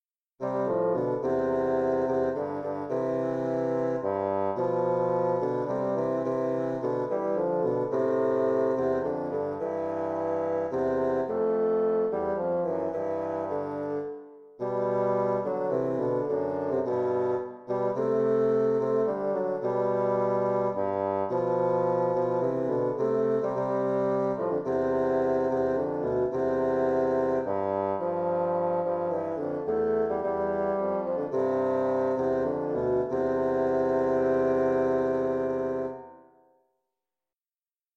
bassoon music